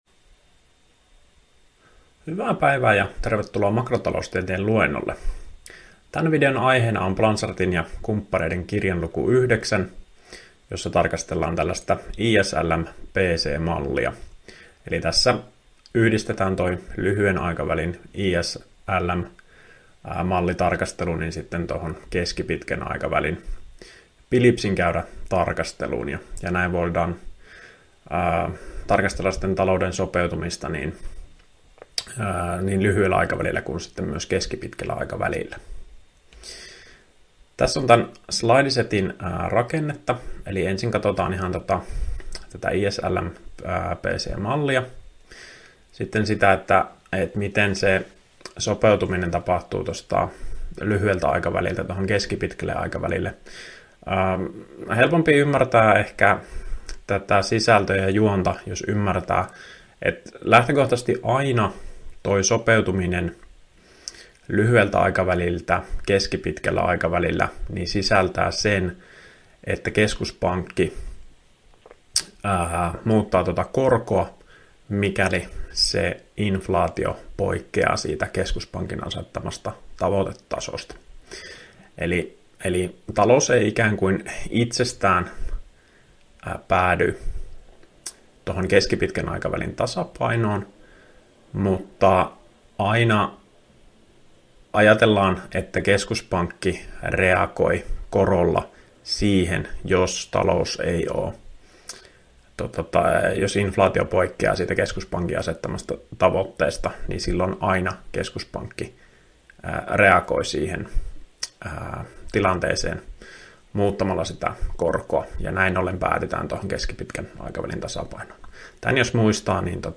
Opintojakson "Makrotaloustiede I" suhdanneosion 8. opetusvideo.